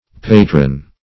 Patron \Pa"tron\, v. t.